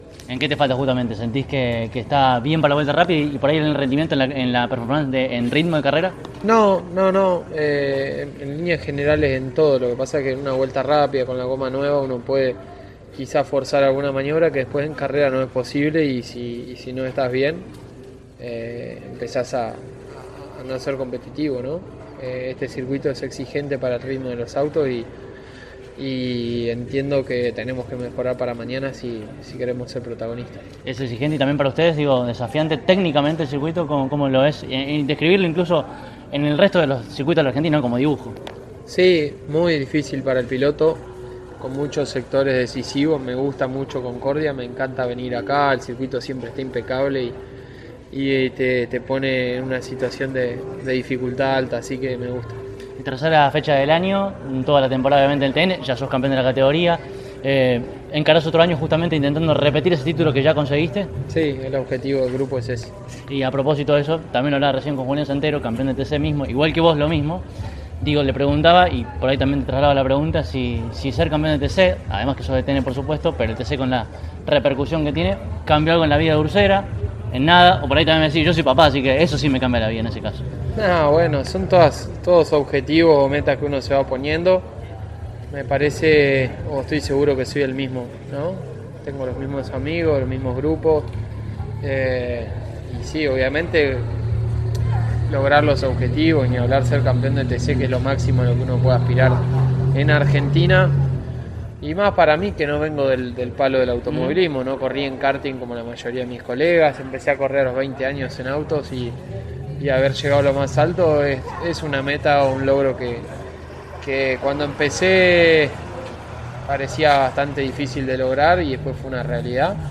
José Manuel Urcera pasó por los micrófonos de CÓRDOBA COMPETICIÓN en el marco de la tercera fecha del año del Turismo Nacional, en Concordia.
En una entrevista muy especial, el piloto rionegrino tocó diversos temas tanto deportivos como de sus inicios en motocross, la paternidad y mucho más.